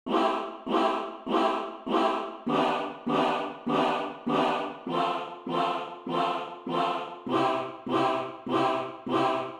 ▼1つの母音のみのサウンド